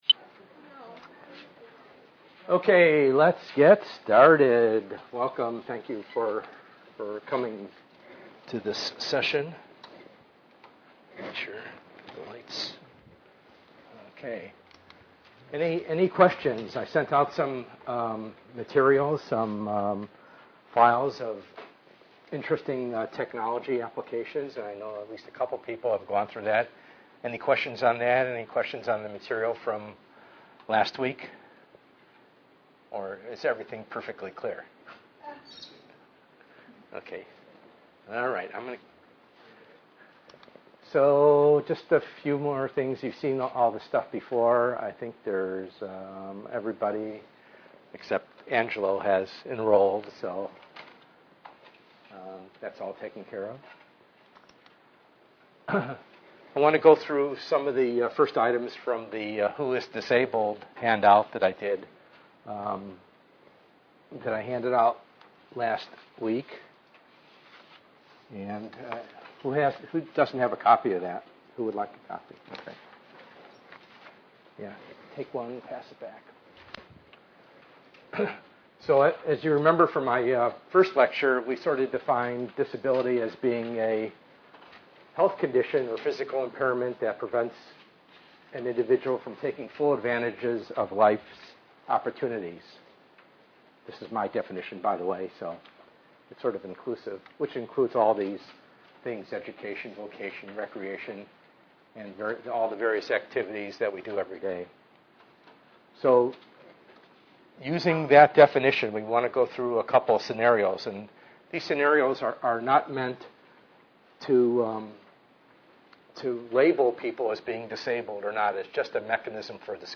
ENGR110/210: Perspectives in Assistive Technology - Lecture 2b